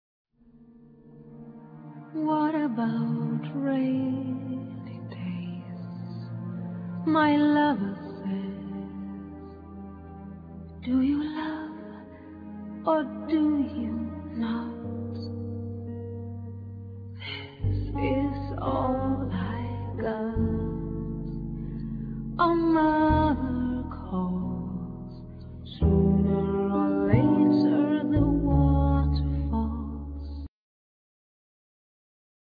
Guitar
Vocals
Soprano saxophone
Drums
Double Bass
Keyboards